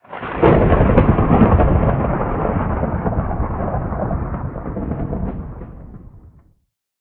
thunder1.ogg